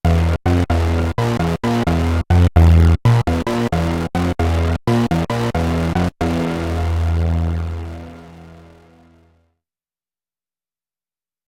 Guess it largely depends on what you mean by “gritty”, but here are some (poorly played) not-pleasant patches I made this afternoon.
No external FX, played straight from the Super6 into an audio interface (TASCAM Model16, which is pretty clean).